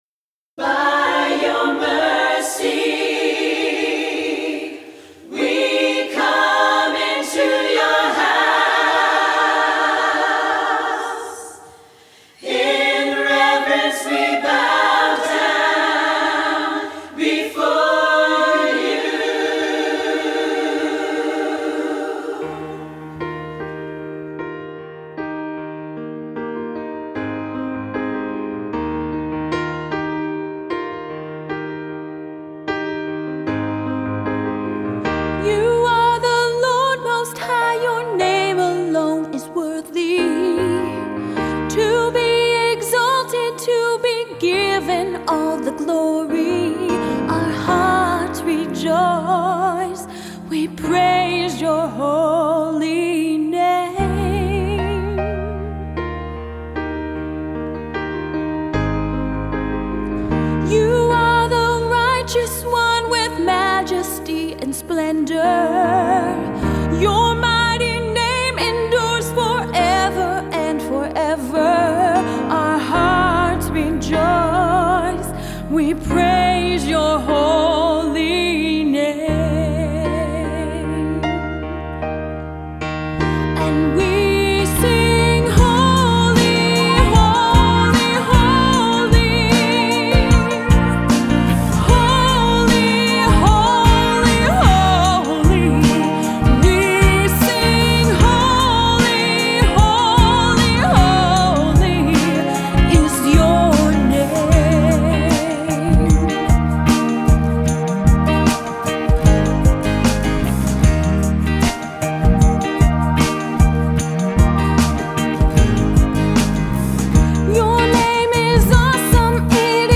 Adult contemporary / AAA / Christian/ Acoustic